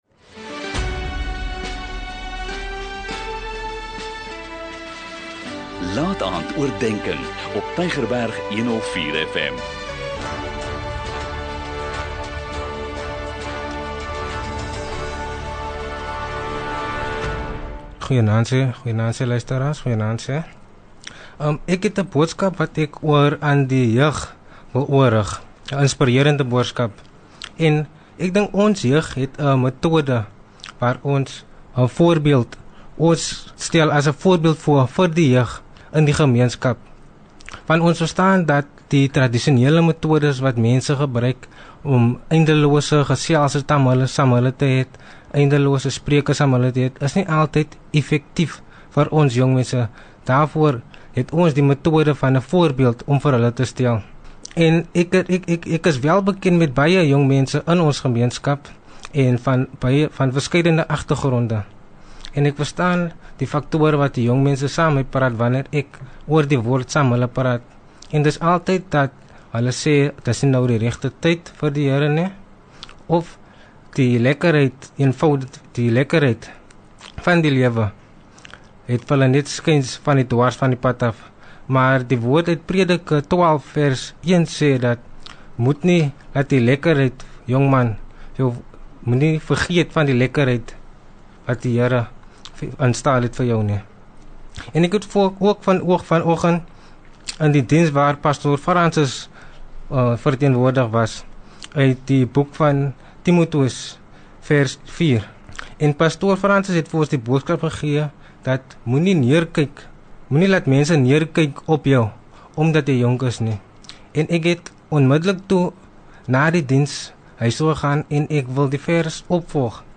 'n Kort bemoedigende boodskap, elke Sondagaand om 20:45, aangebied deur verskeie predikers.